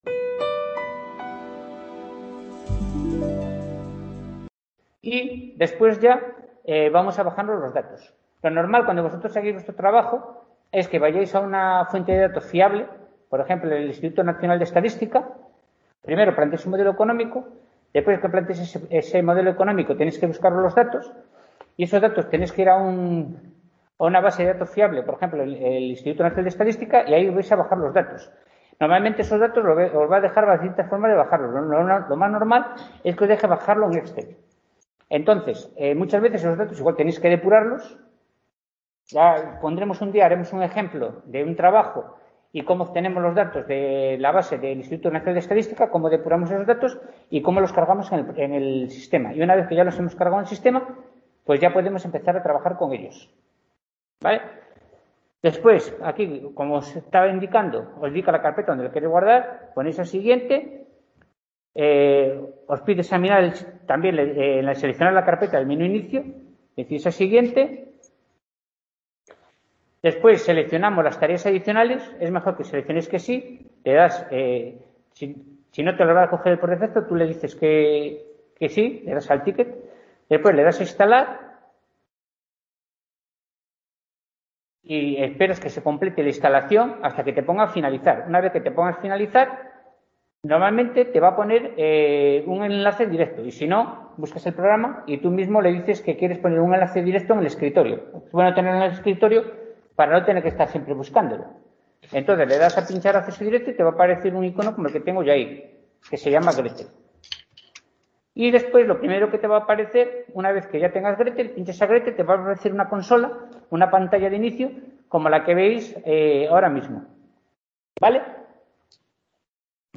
Seminario Econometría 2021 sesión práctica I | Repositorio Digital